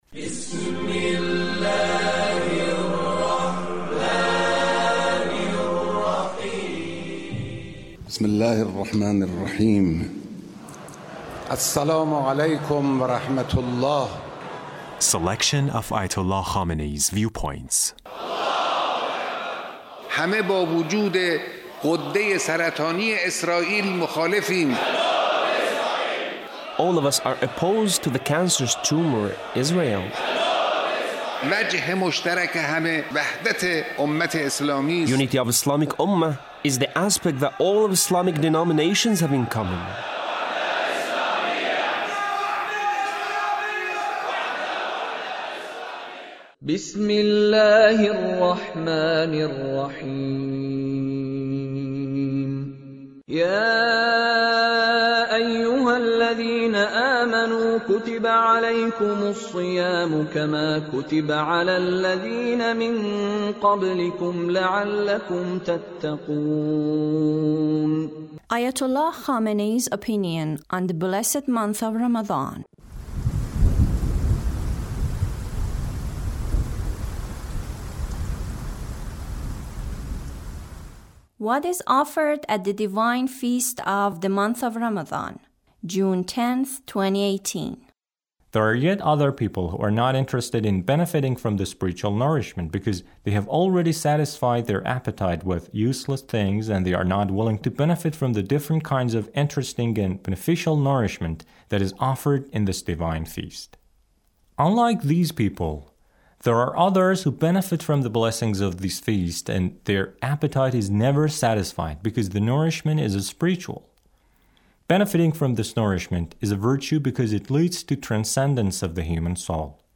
Leader's speech (52)